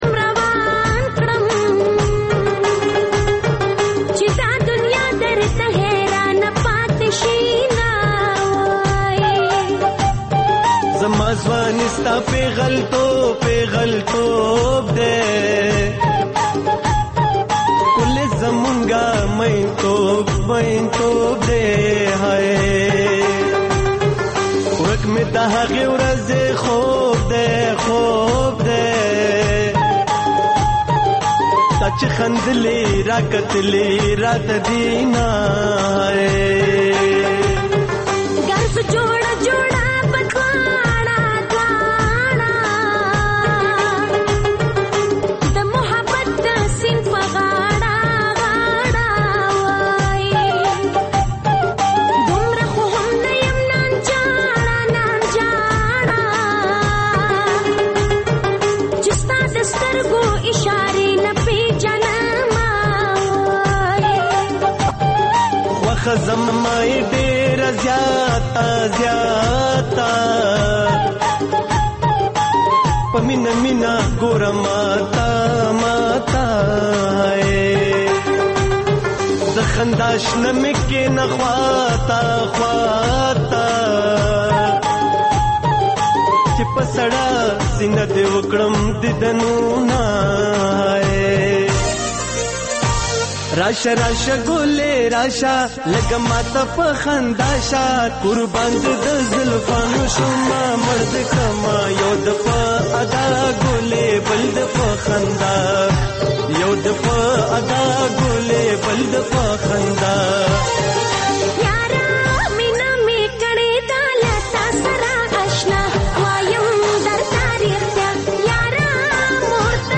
د سندرو مېلمانه ( موسیقي)